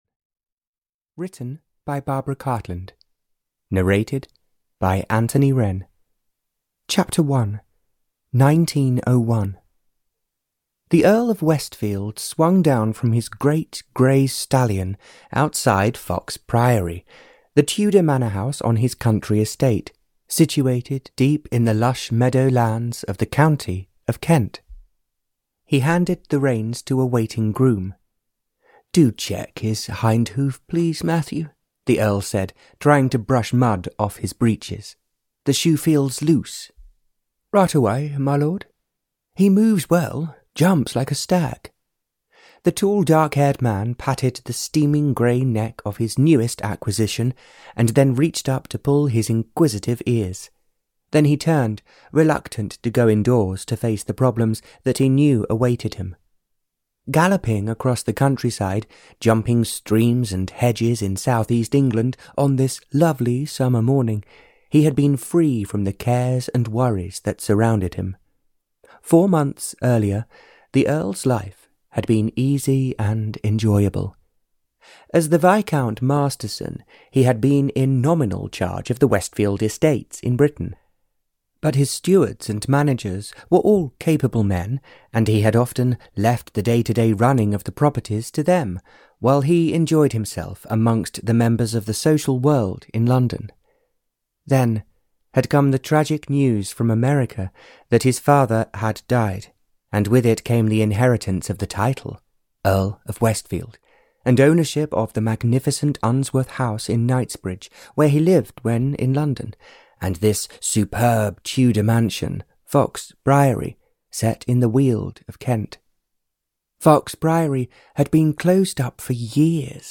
Audio knihaMusic From Heaven (Barbara Cartland's Pink Collection 144) (EN)
Ukázka z knihy